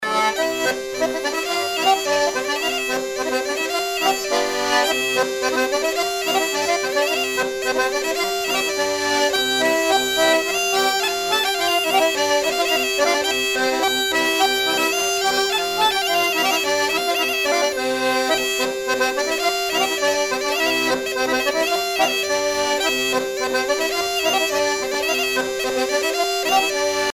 danse : menuet congo
Pièce musicale éditée